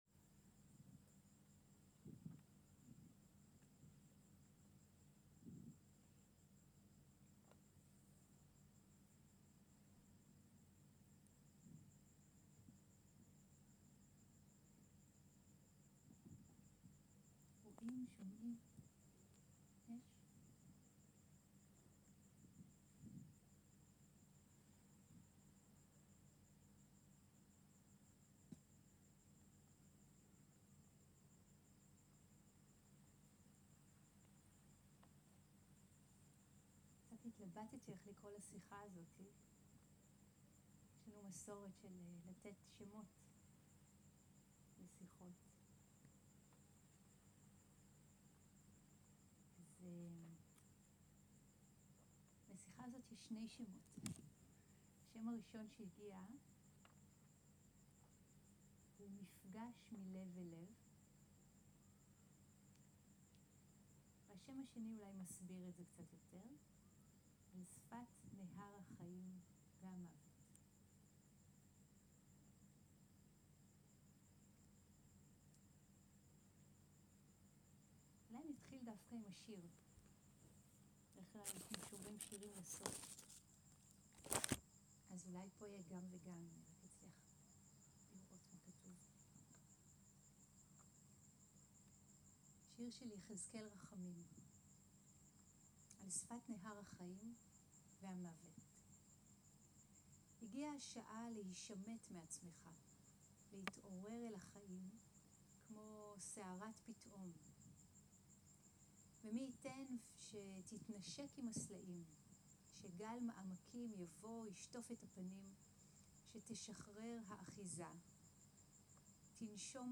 Dharma talk